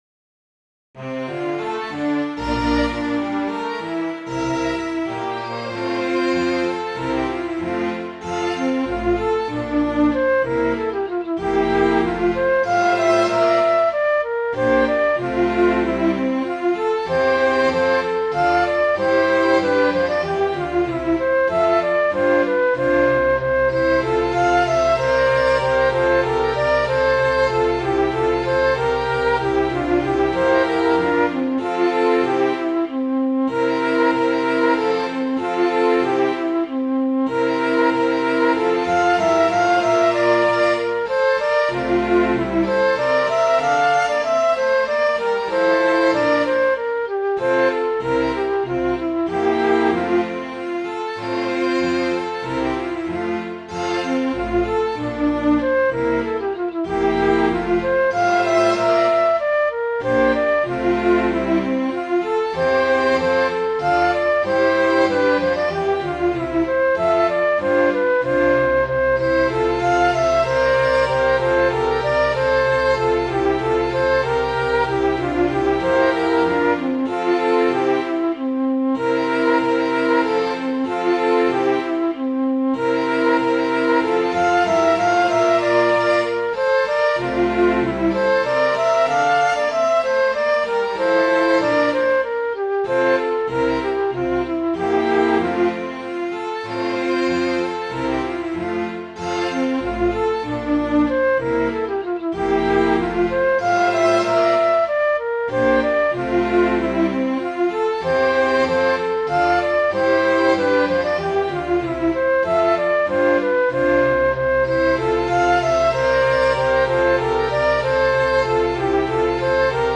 Midi File, Lyrics and Information to The Token